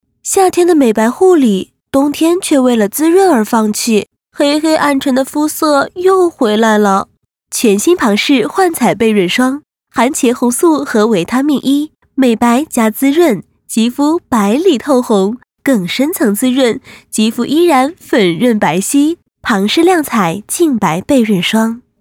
【广告】年轻时尚-旁氏幻彩亮白倍润霜